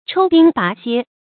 抽丁拔楔 chōu dīng bá xiē
抽丁拔楔发音
成语注音 ㄔㄡ ㄉㄧㄥ ㄅㄚˊ ㄒㄧㄝ